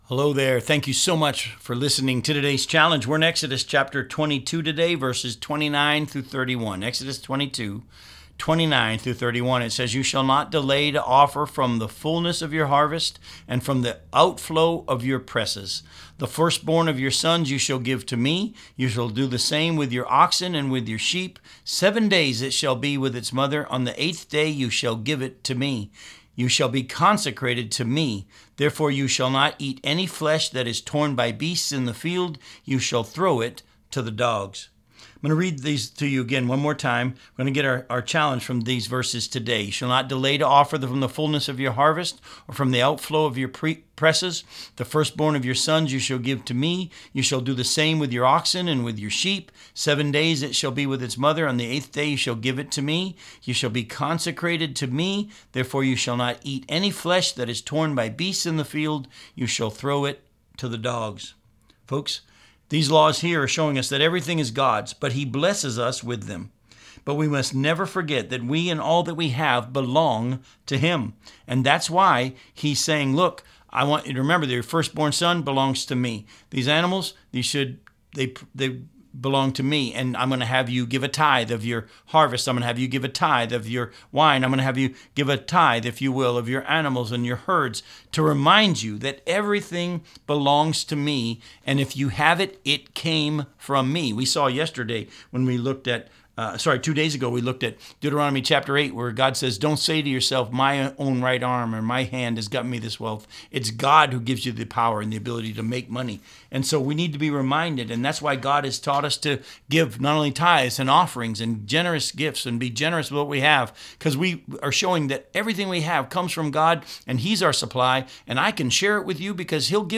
radio program aired on WCIF 106.3 FM in Melbourne, Florida